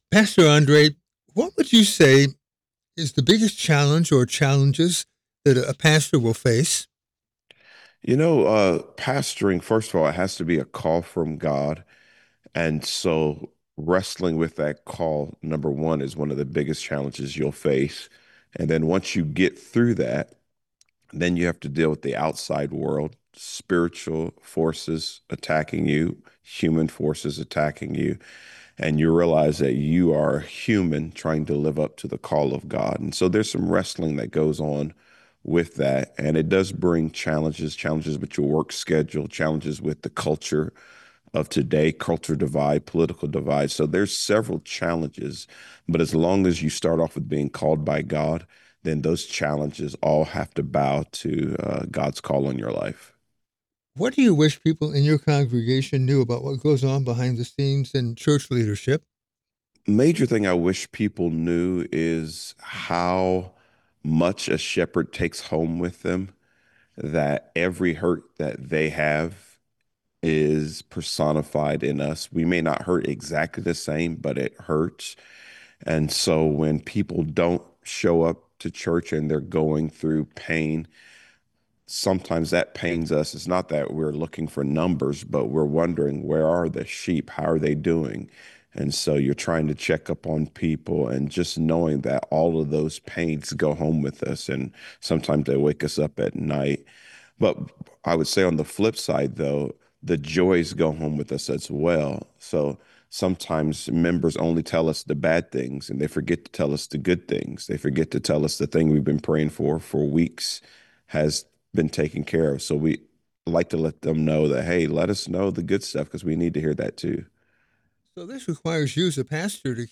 He also acknowledges that pastors need constant grace from the congregation, as well as God, because sometimes things can "slip through the cracks." Here's our podcast: